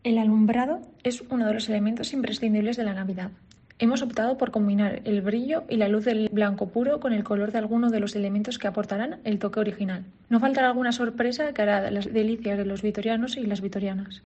María Nanclares, concejala de Promoción Económica, Empleo, Comercio y Turismo